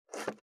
490切る,包丁,厨房,台所,野菜切る,咀嚼音,ナイフ,調理音,まな板の上,料理,